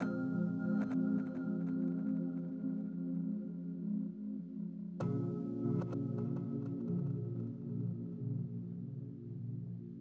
MELODICS 2.wav